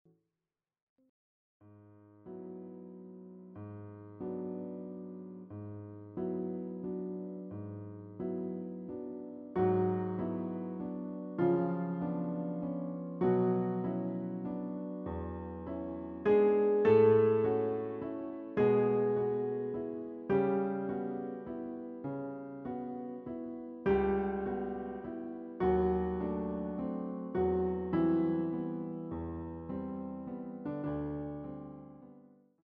CD quality digital audio Mp3 file
using the stereo sa1mpled sound of a Yamaha Grand Piano.